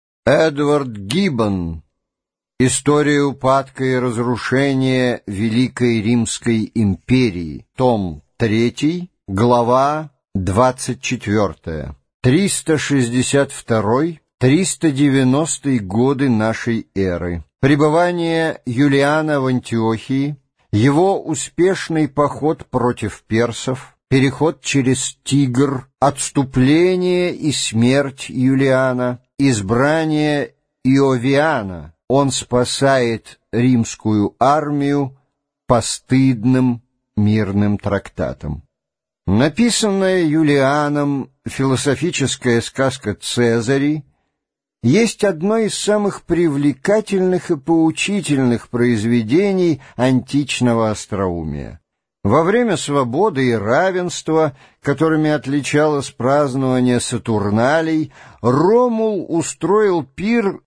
Аудиокнига История упадка и разрушения Римской Империи. Том 3 | Библиотека аудиокниг